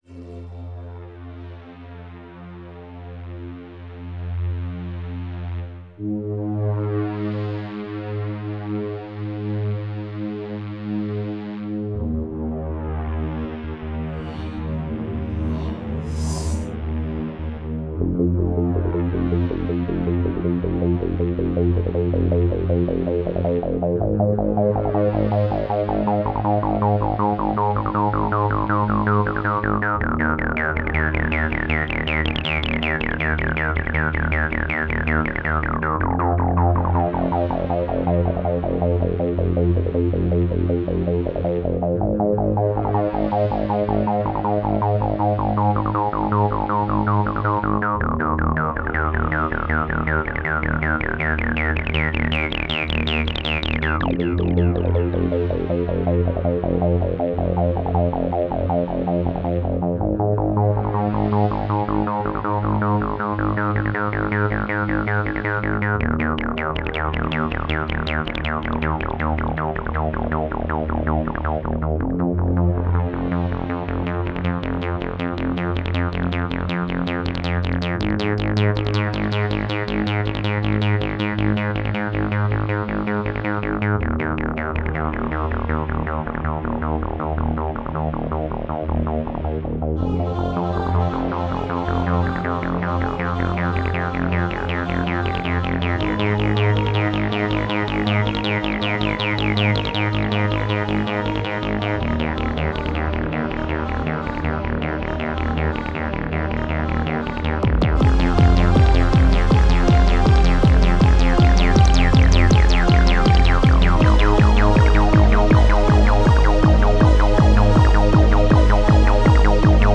Viel Acid und viel Rhythmus – das war schon prägend!